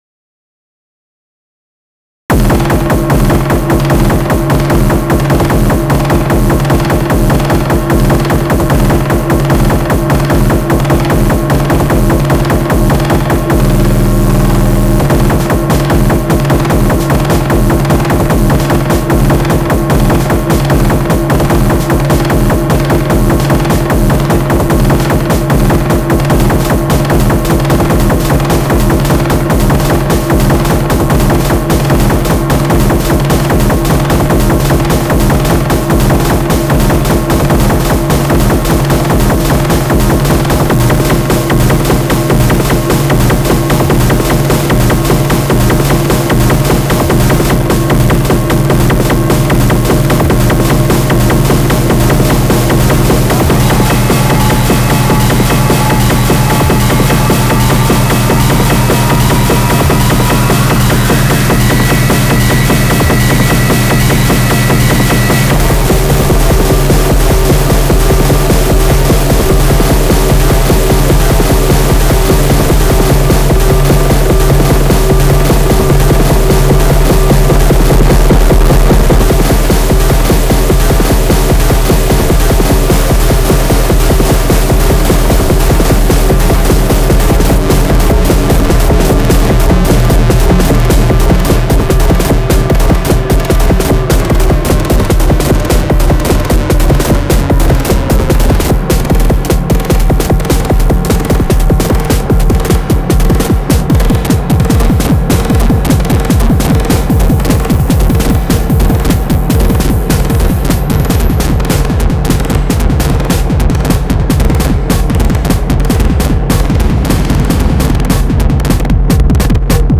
Cycles-core? You mean like this one (made during my first day with the cycles)